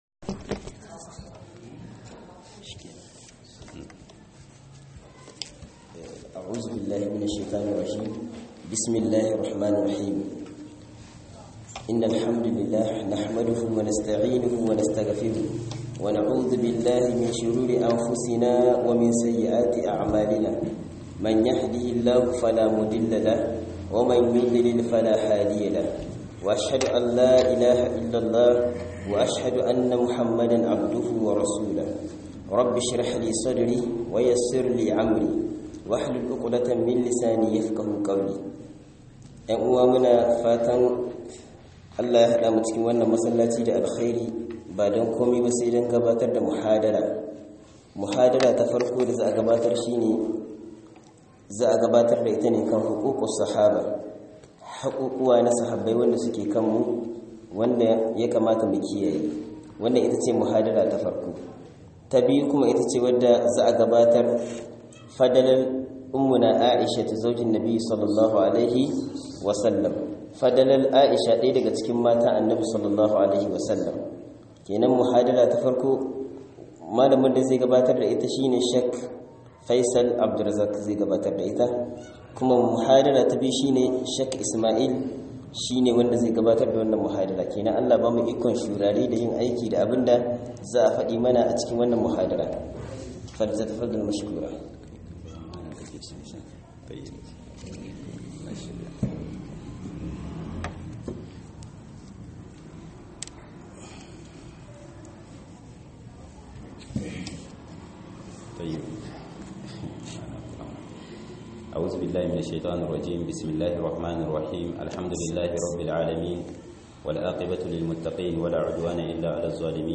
HAKI GOMA AKAN SAHABAI DA FALAR A,ICHA RA 2022-02-01_18'44'21' - MUHADARA